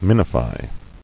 [min·i·fy]